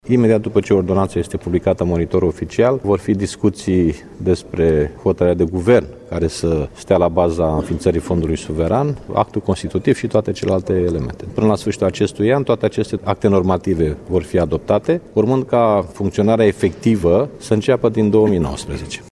Anunţul a fost făcut de preşedintele social-democrat, Liviu Dragnea, după o şedinţă a coaliţiei de guvernare, PSD-ALDE, care a durat mai bine de trei ore. Liviu Dragnea a precizat că, săptămâna viitoare, Guvernul va adopta o ordonanţă de urgenţă care va reglementa cadrul legal pentru înfiinţarea Fondului Suveran de Dezvoltare şi Investiţii din România.
DRAGNEA-1.mp3